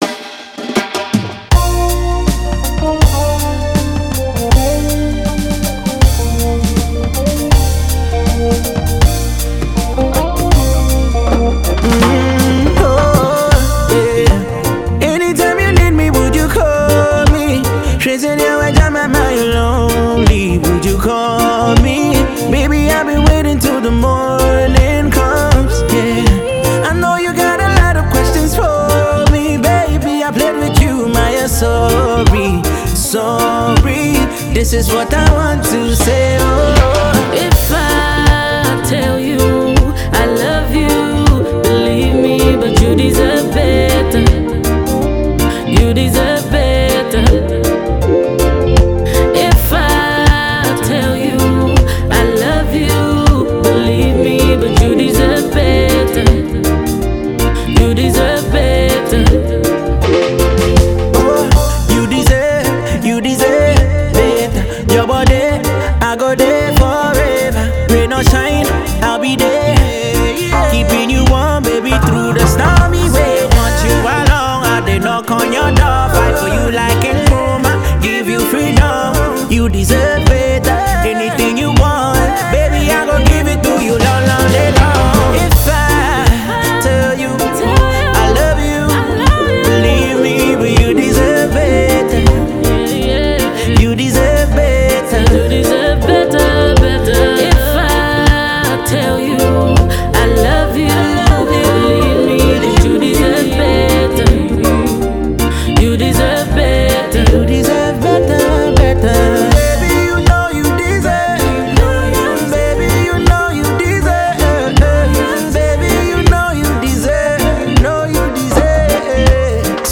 lover’s rock single